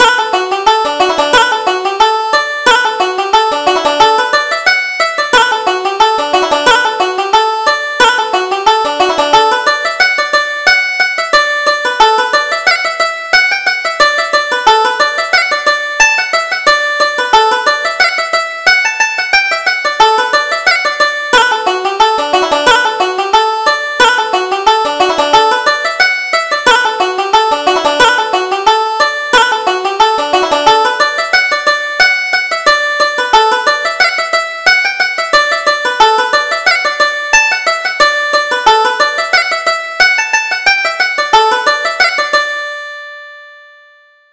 Reel: The Hag by the Fire